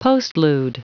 Prononciation du mot postlude en anglais (fichier audio)
Prononciation du mot : postlude